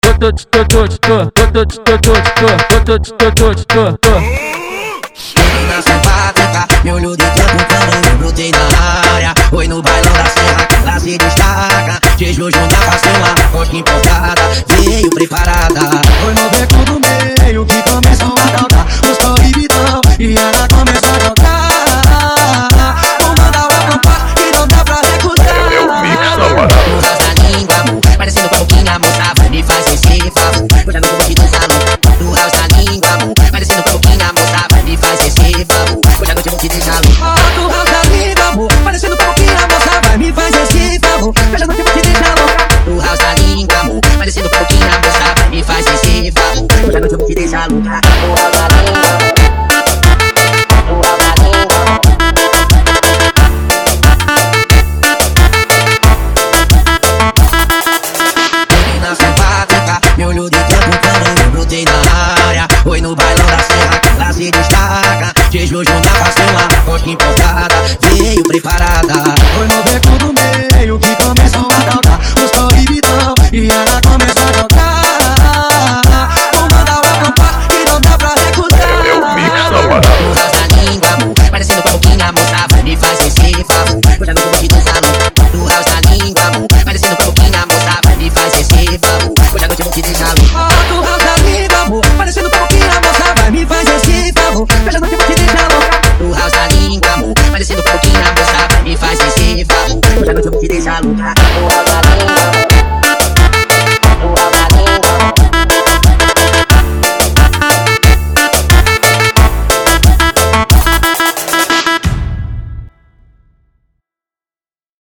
Tecno Melody 2023